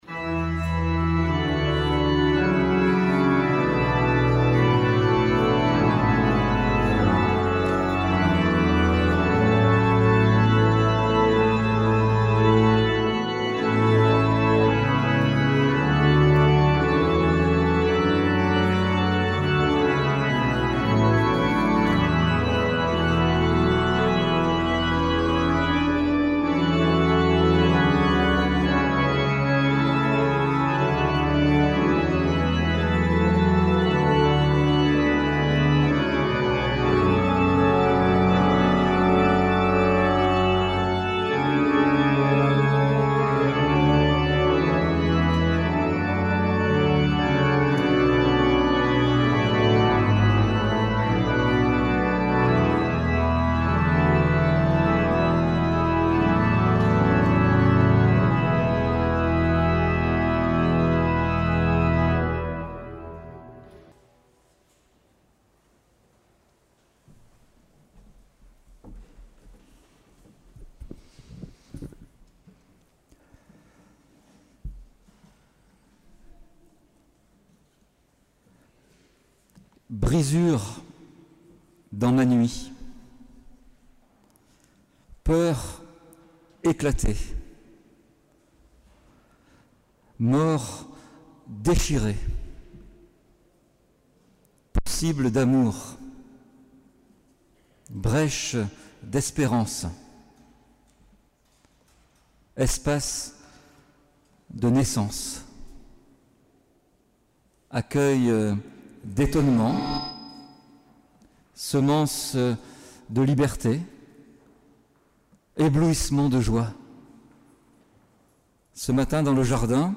"Qui cherches-tu ? " Audio Culte de Pâques